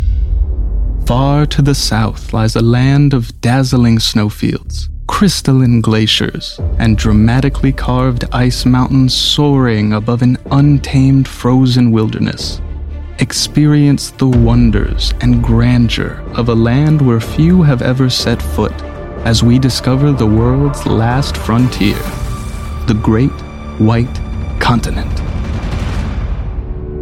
Antarctica - Narration - Quiet Mystery